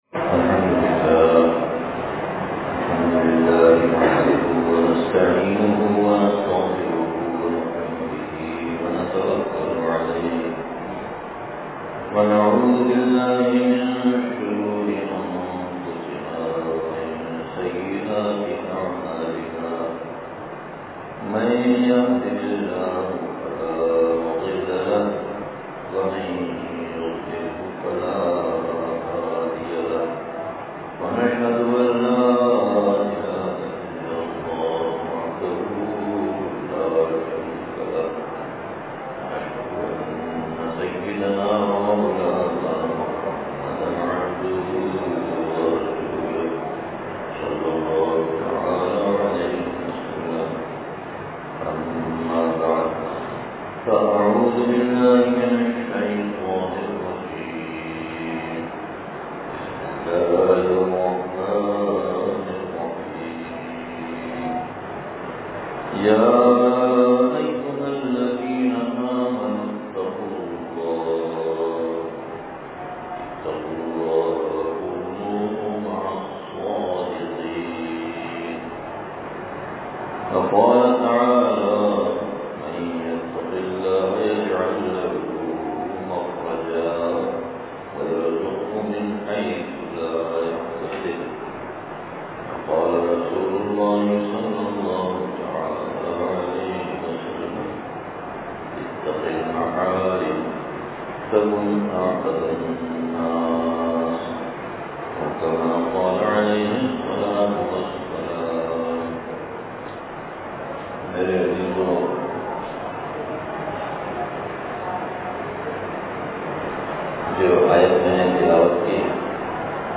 جمعہ بیان مدرسہ ابو بکر مرکز امداد و اشرف نزد معمار ہاؤسنگ کراچی